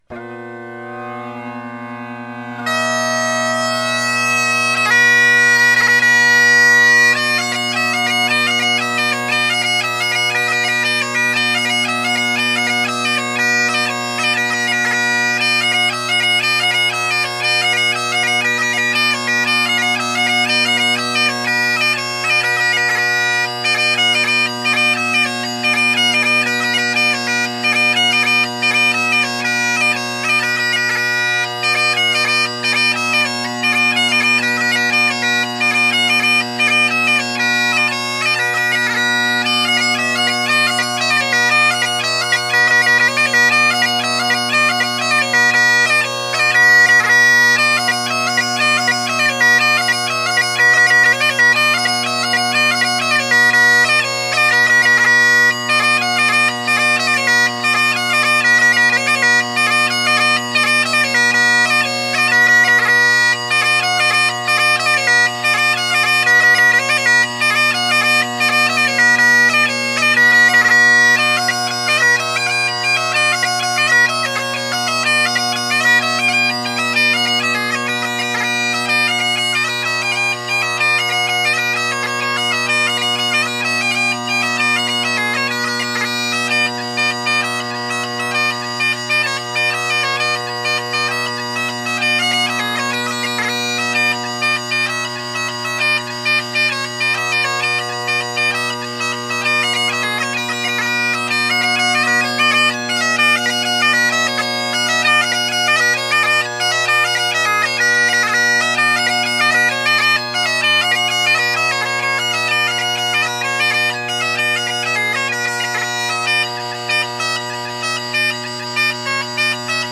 Great Highland Bagpipe Solo